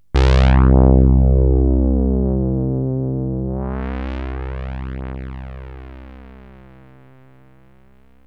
SYNTH BASS-2 0008.wav